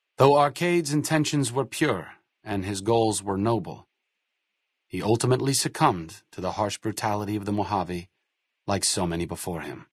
Category:Fallout: New Vegas endgame narrations Du kannst diese Datei nicht überschreiben. Dateiverwendung Die folgenden 2 Seiten verwenden diese Datei: Arcade Gannon Enden (Fallout: New Vegas) Metadaten Diese Datei enthält weitere Informationen, die in der Regel von der Digitalkamera oder dem verwendeten Scanner stammen.